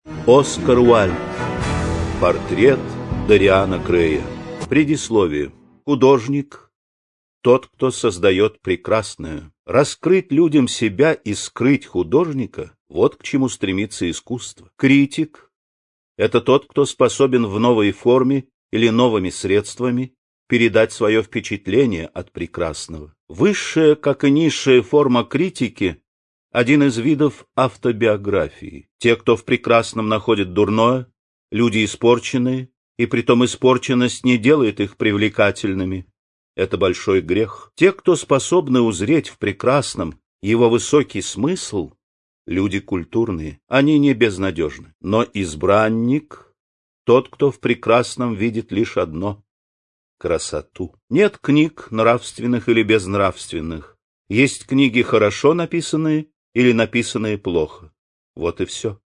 Аудиокнига Портрет Дориана Грея | Библиотека аудиокниг